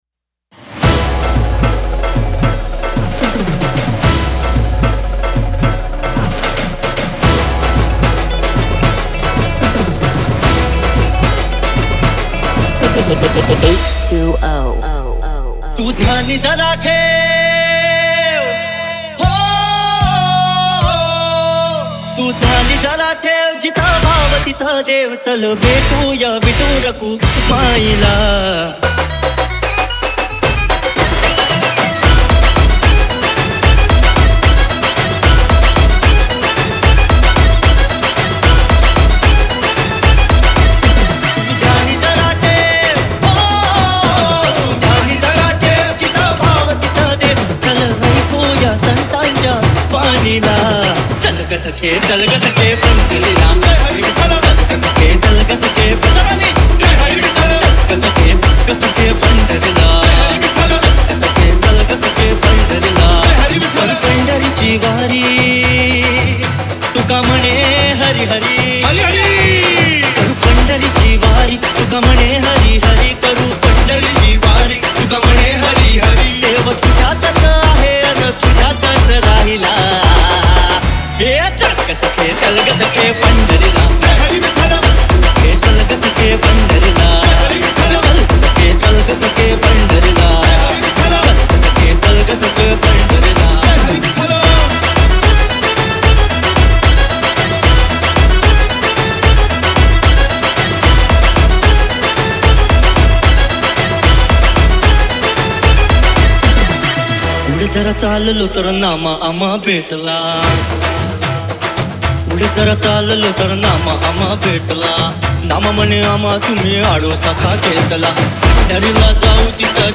• Category:Marathi Single